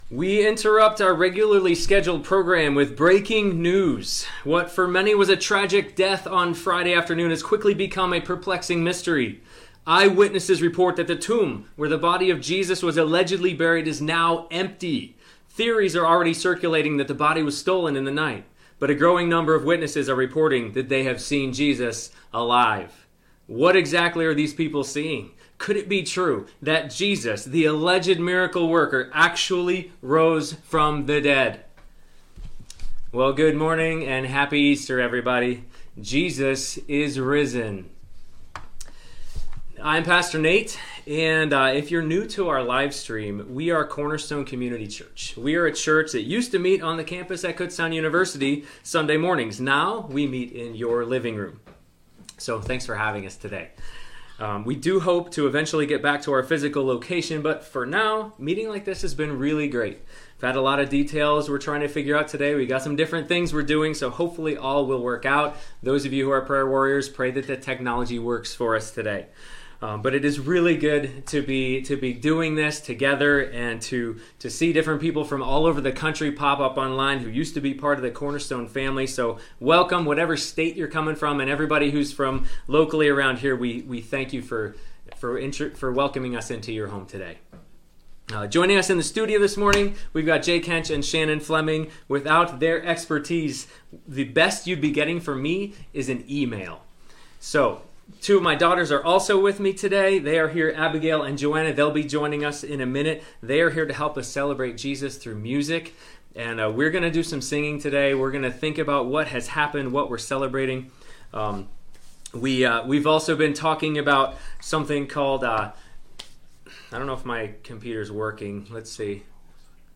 Series: CCC Sermons
Service Type: Sunday Morning